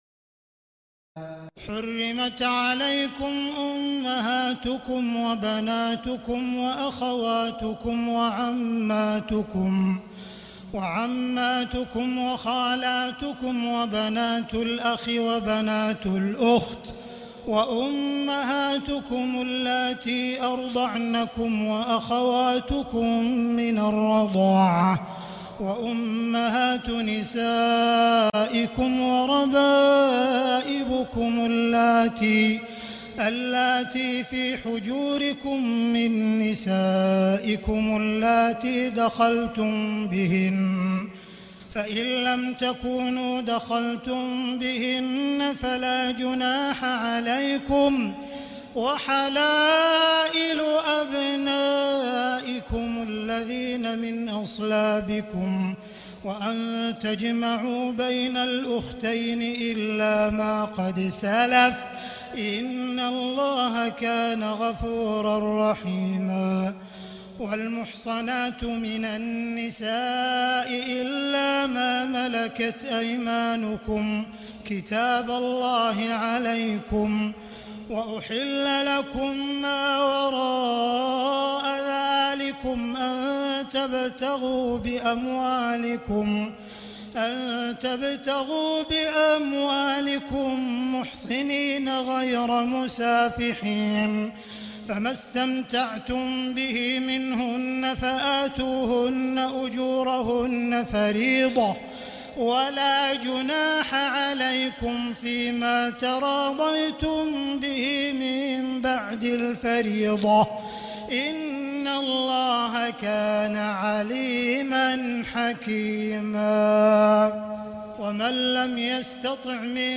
تراويح الليلة الرابعة رمضان 1439هـ من سورة النساء (23-87) Taraweeh 4 st night Ramadan 1439H from Surah An-Nisaa > تراويح الحرم المكي عام 1439 🕋 > التراويح - تلاوات الحرمين